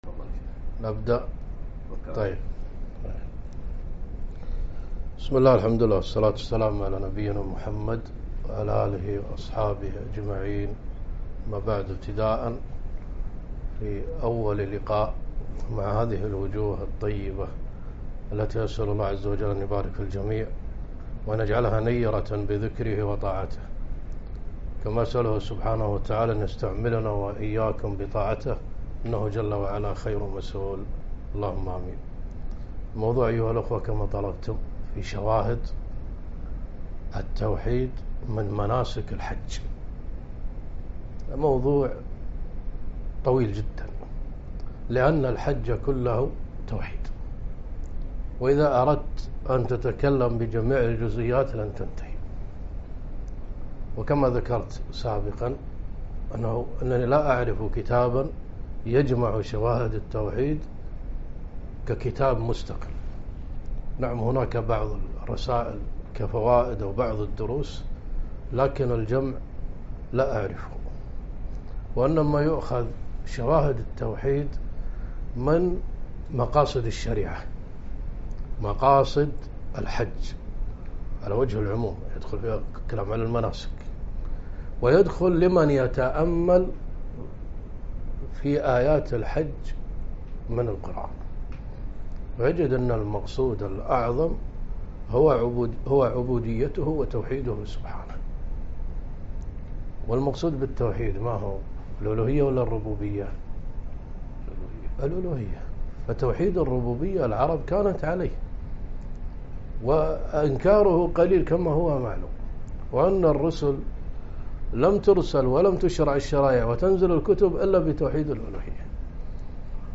محاضرة - شواهد التوحيد من مناسك الحج والهدي والأضاحي